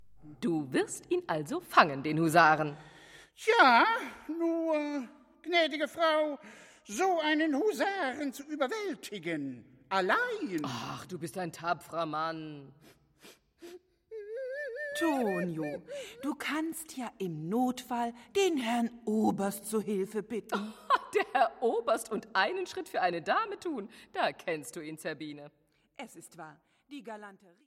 Die vorliegende Einspielung beruht auf der Uraufführung des gesamten Werks bei der Theater&Philharmonie Thüringen und wurde im September 2008 im Konzertsaal der Bühnen der Stadt Gera aufgenommen.